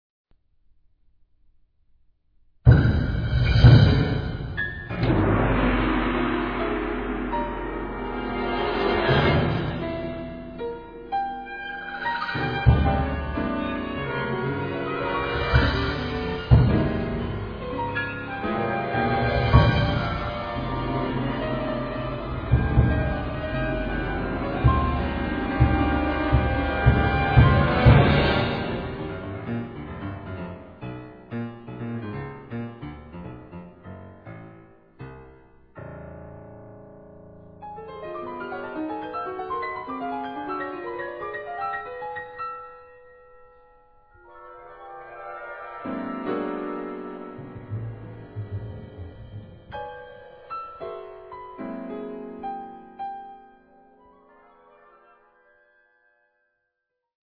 Classical
Avant Garde
(solo piano & orchestra)
Solo Piano